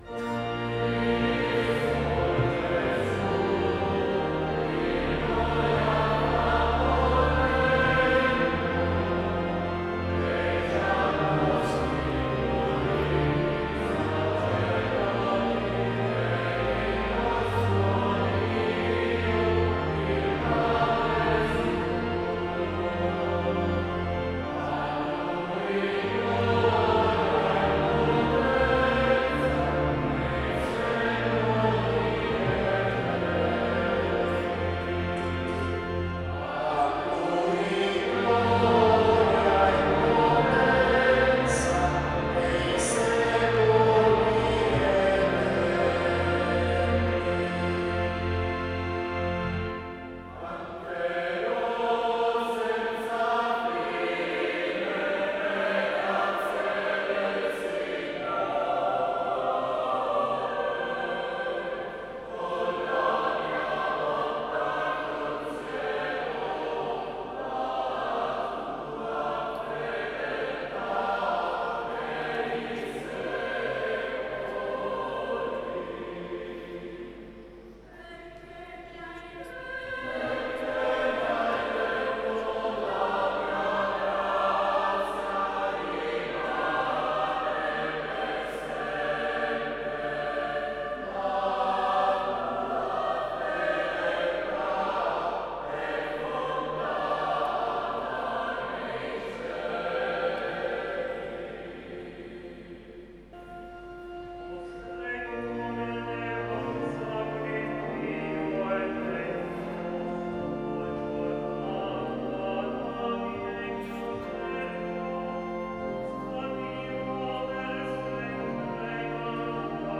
Gallery >> Audio >> Audio2016 >> Messa Crismale >> 01-Ingresso MessaCrisma2016